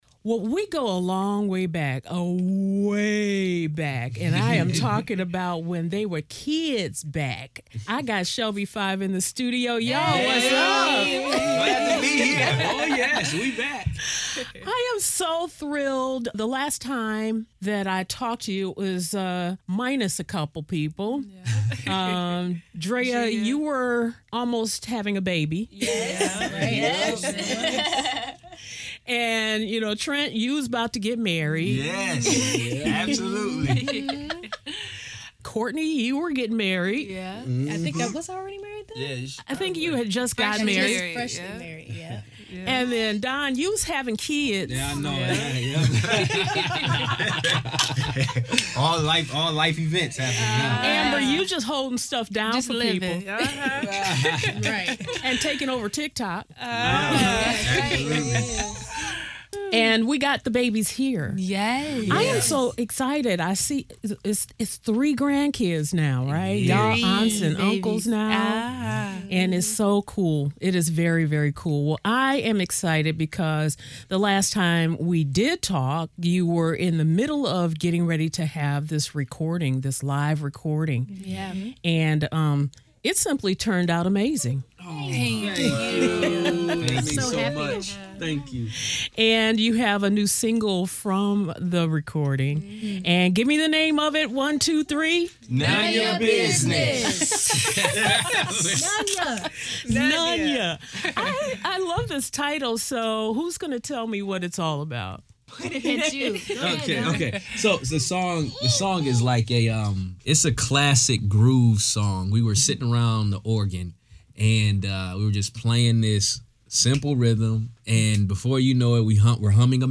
Recently, I had the chance to catch up with the quintet. We talked about the changes in their lives as not only singers but business owners, minister and pastors.